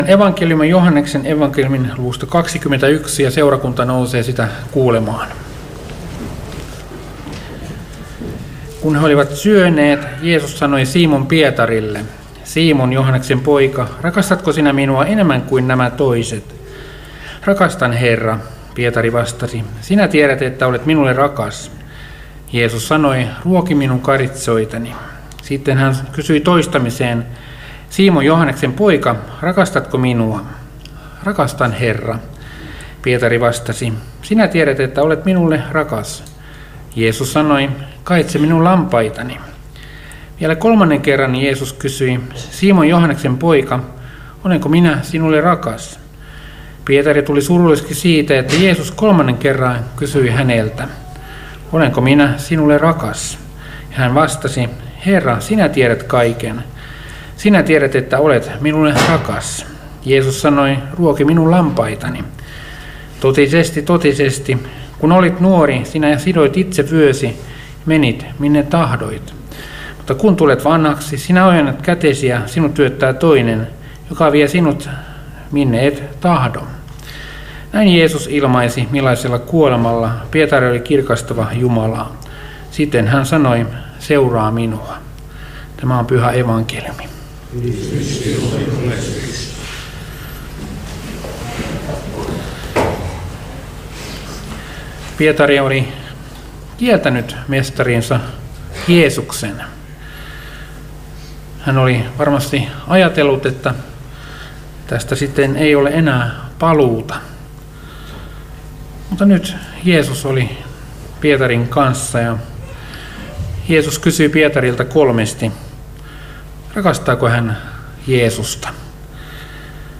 Karkku